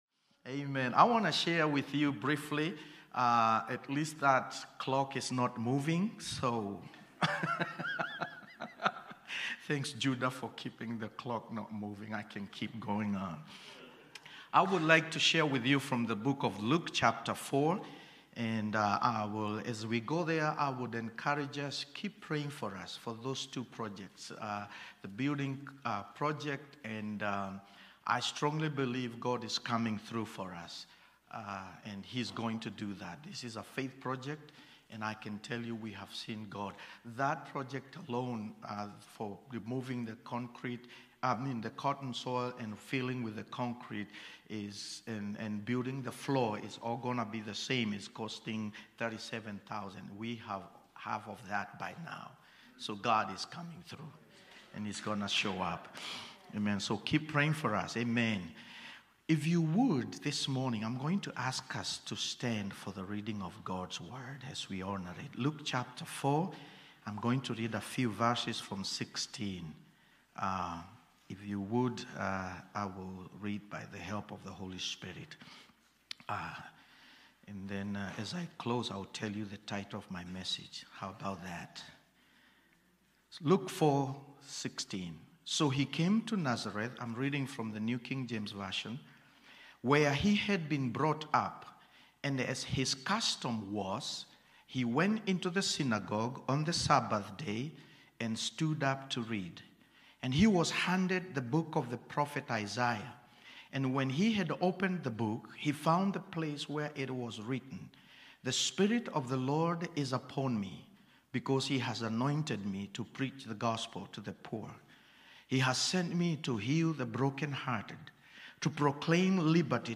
Topic Spiritual Growth , Trusting God Book Luke Watch Listen Save Cornerstone Fellowship Sunday morning service, livestreamed from Wormleysburg, PA.